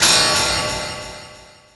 shield_02.wav